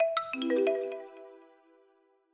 Longhorn 8 - Proximity Notification.wav